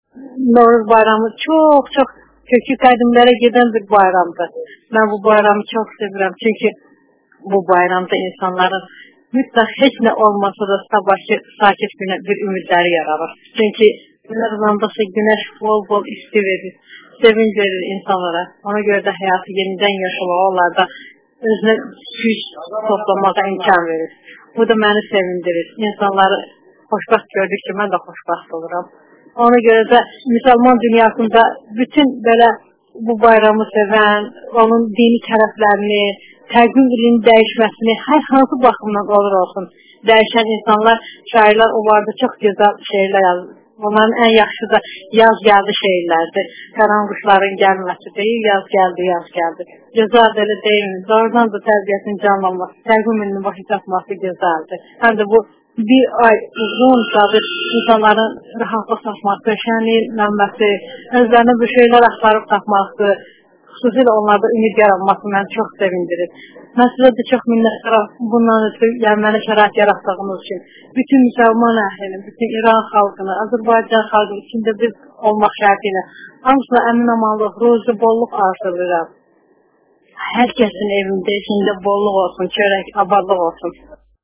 Novruz Bayramı Azərbaycanda + reportaj (Audio)